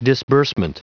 Prononciation du mot disbursement en anglais (fichier audio)
Prononciation du mot : disbursement